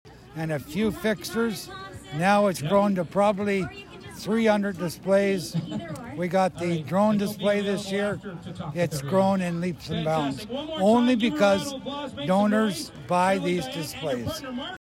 Chair of the Festival of Lights this year is Councillor Garnet Thompson who said it all started on the front lawn of an east end Belleville home in 1959.